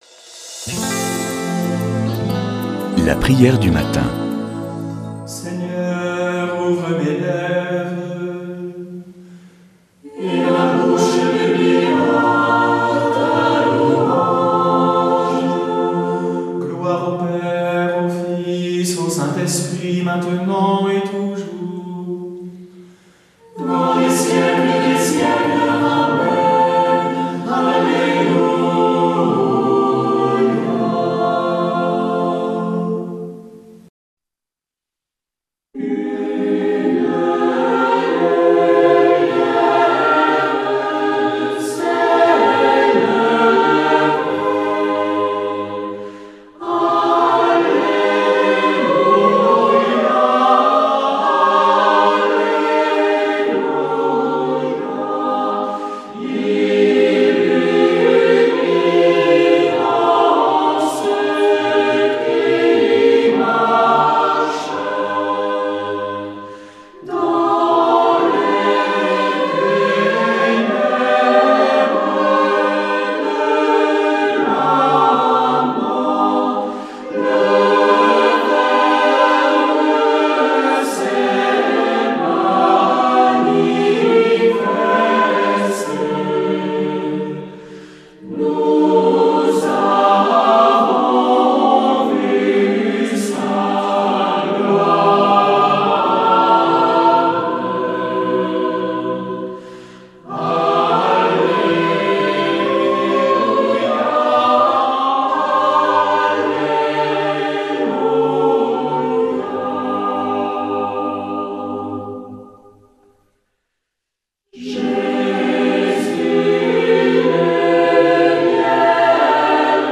Prière du matin
FRAT MONASTIQUE DE JERUSALEM